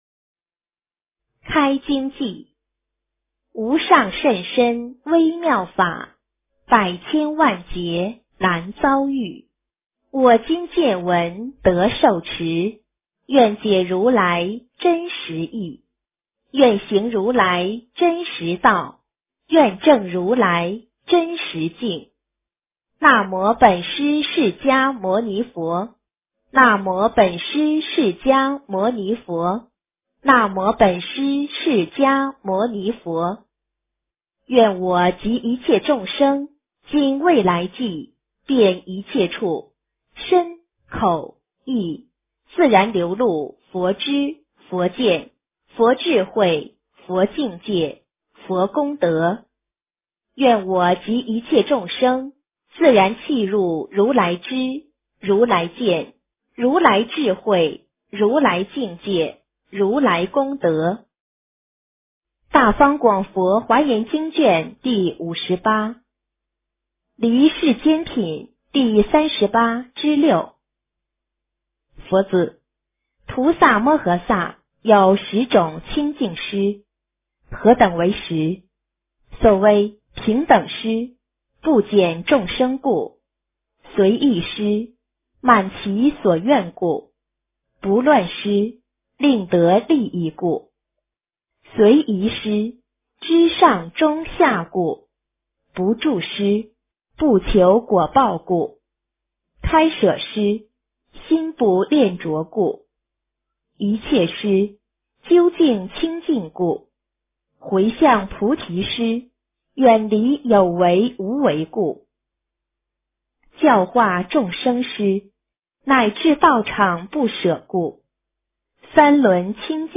华严经58 - 诵经 - 云佛论坛